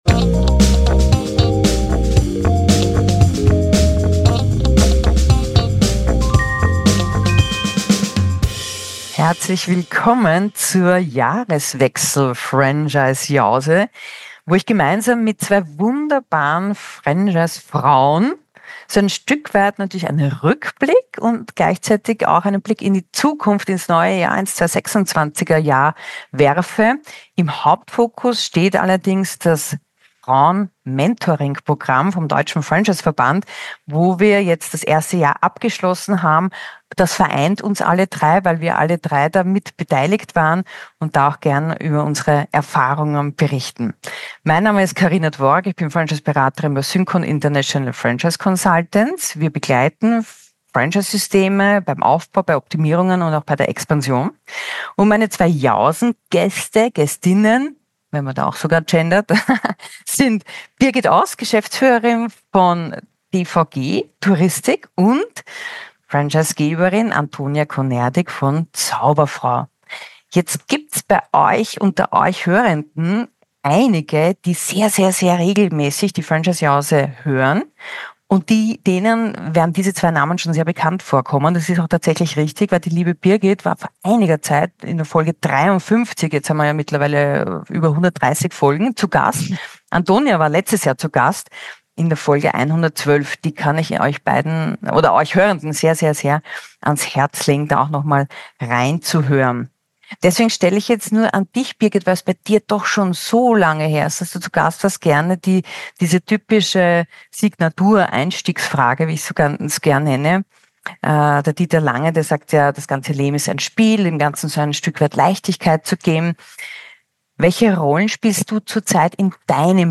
Ein Gespräch über FIF Mentoringprogramm, Transparenz, Marke, Energiemodell und Persönlichkeitsentwicklung